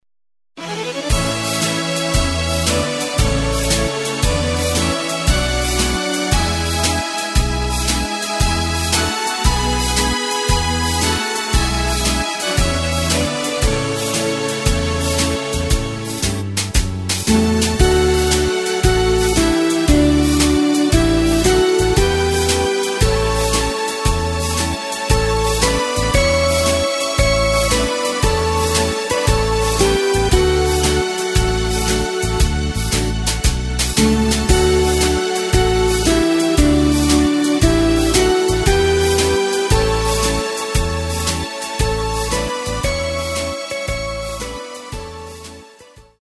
Takt:          4/4
Tempo:         115.00
Tonart:            A
Schlager aus dem Jahr 1972!